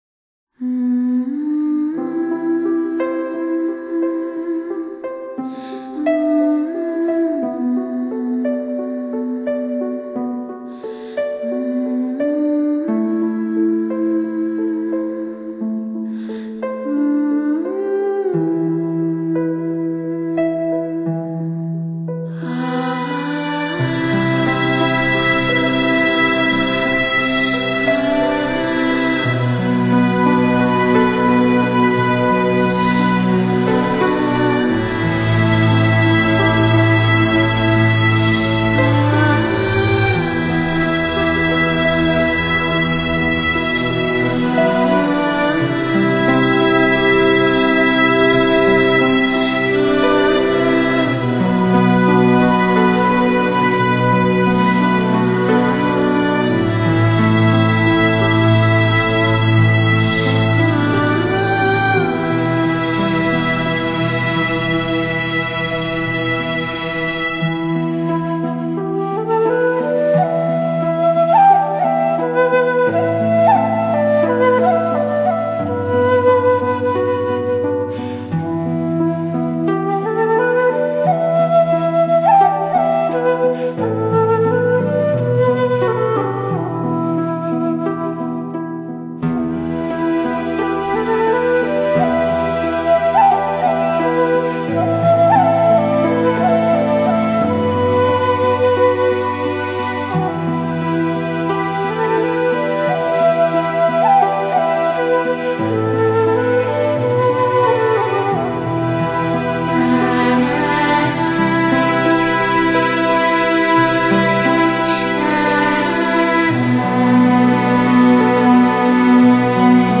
悠悠女声中，忆起边城——凤凰。